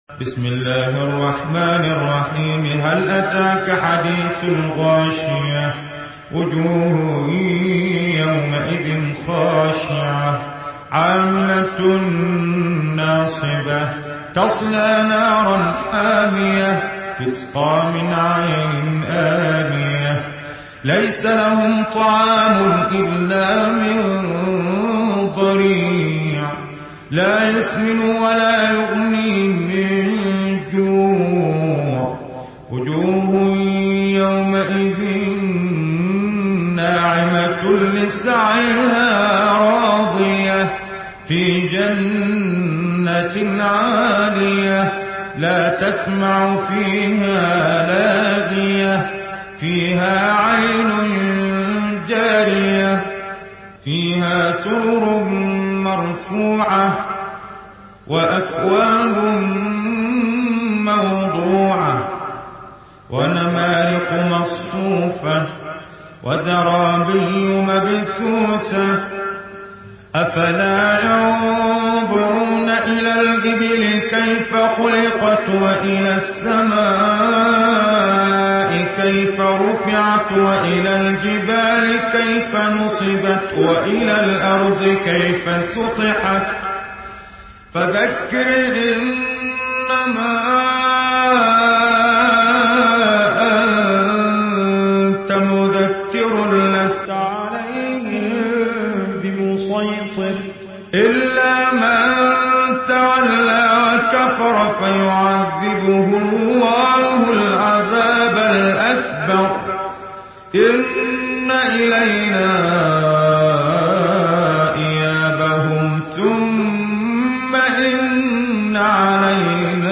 مرتل